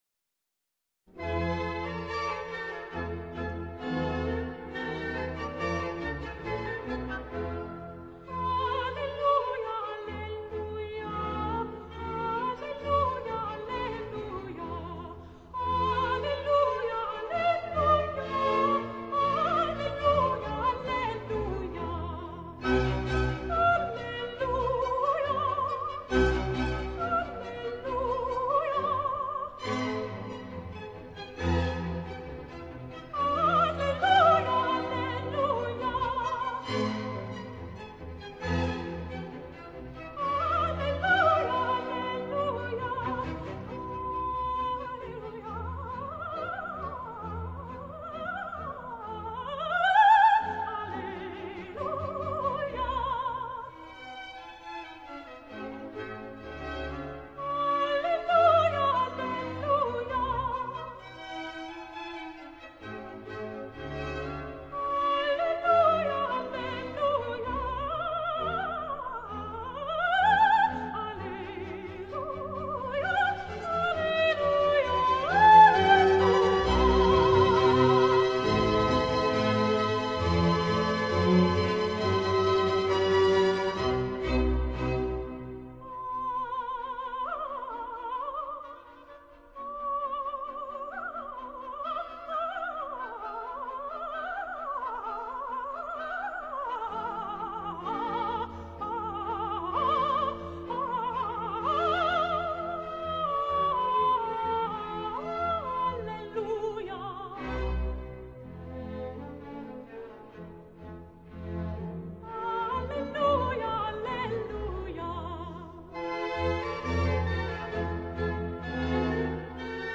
Vocal, Classical